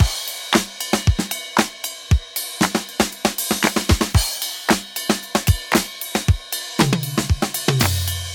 • 115 Bpm Drum Beat C Key.wav
Free drum loop sample - kick tuned to the C note. Loudest frequency: 3455Hz
115-bpm-drum-beat-c-key-ASo.wav